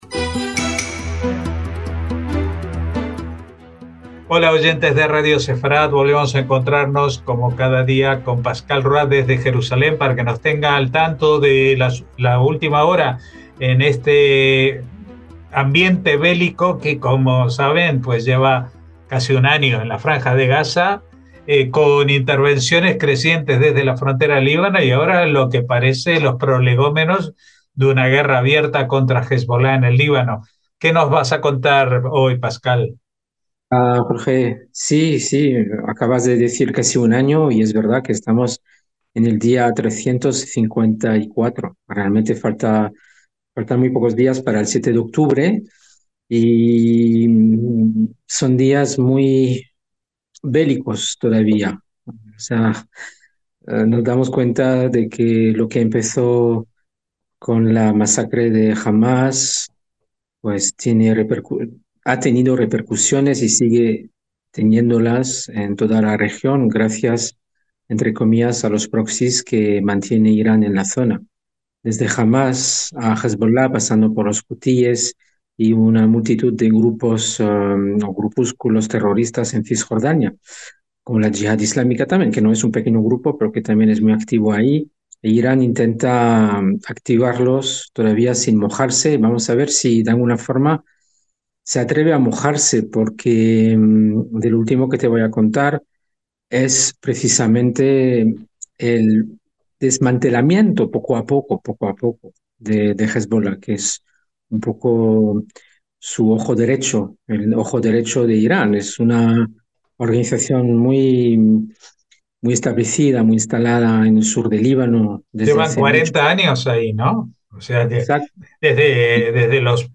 NOTICIAS CON COMENTARIO A DOS